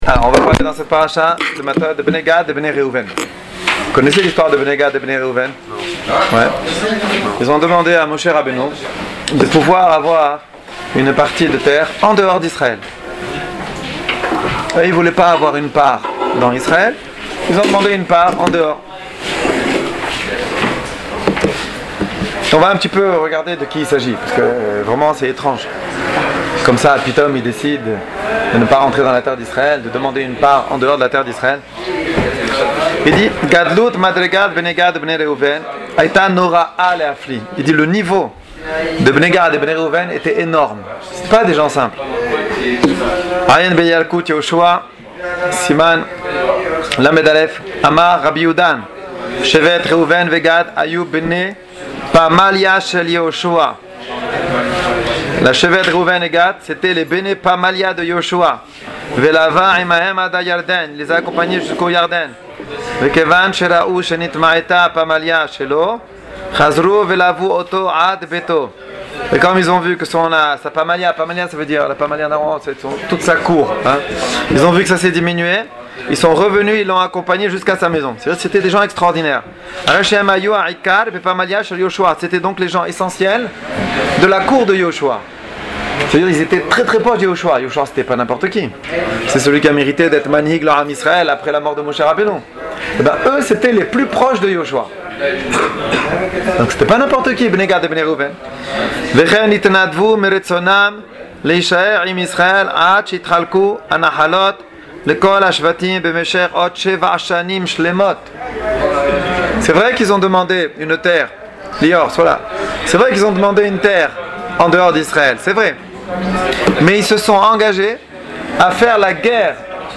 Cours audio